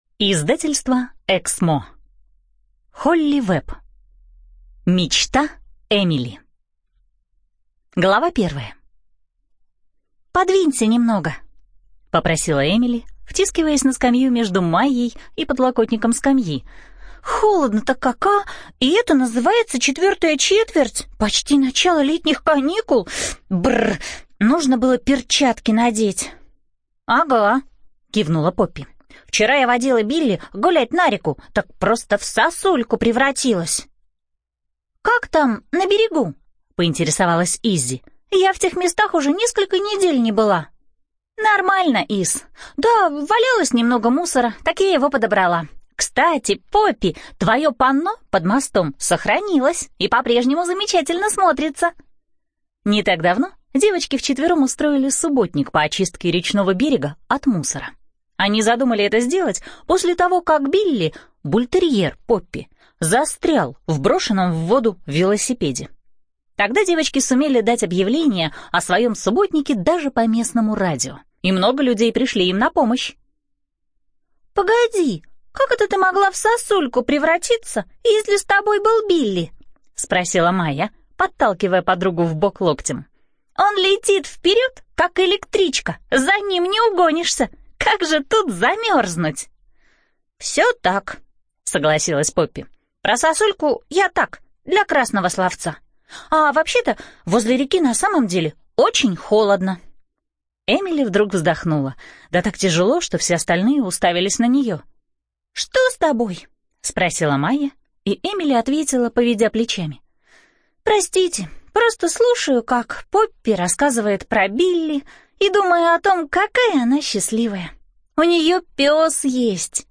ЖанрДетская литература
Студия звукозаписиЭКСМО